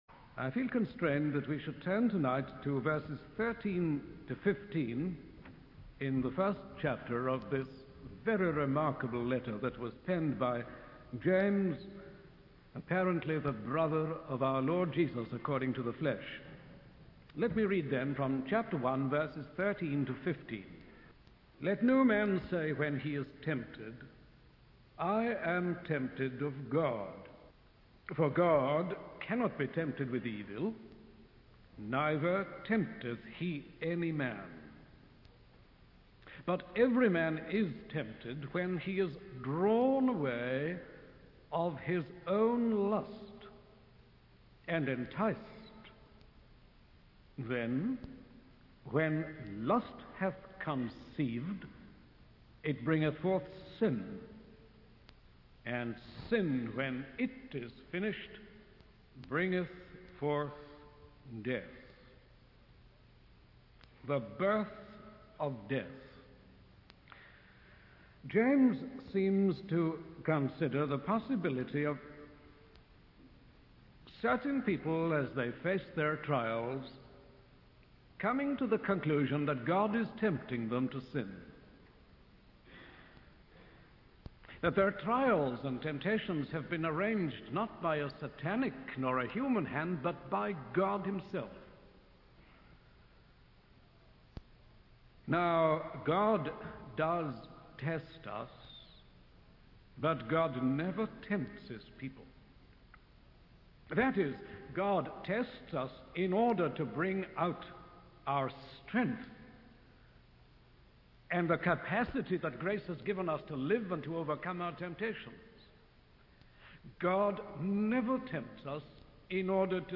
In this sermon, the preacher discusses the concept of sin and its consequences.